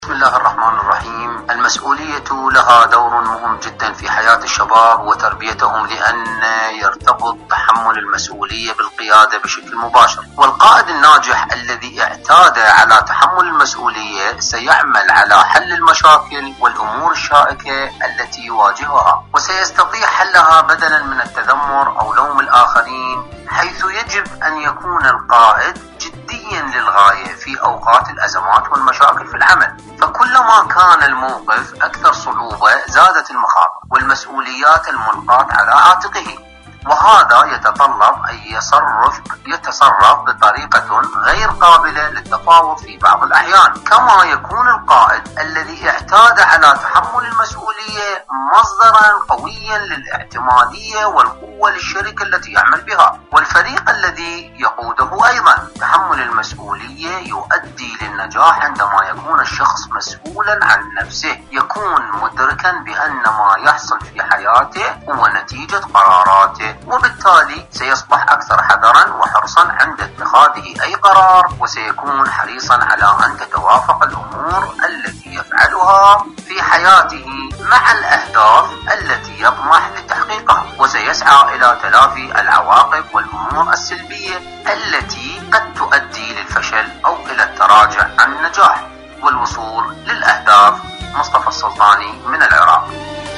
مشاركة واتساب صوتية من